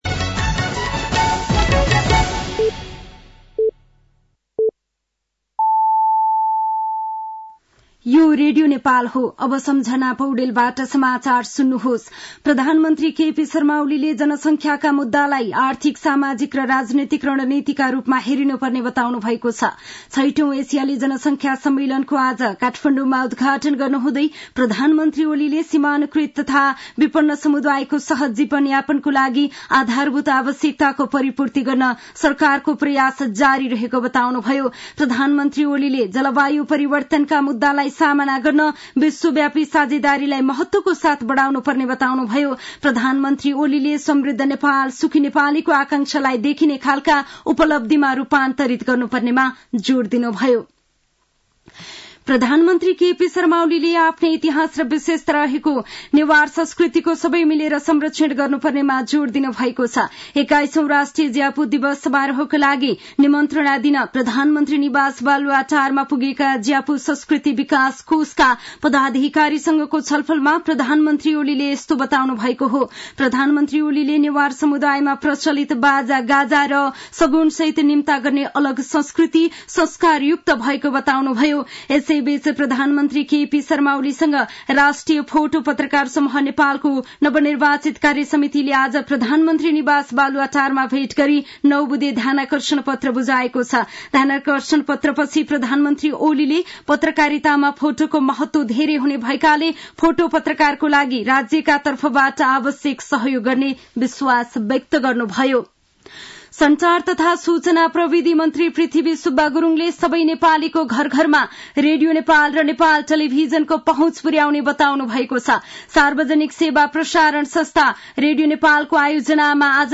साँझ ५ बजेको नेपाली समाचार : १३ मंसिर , २०८१
5-pm-nepali-news-8-12.mp3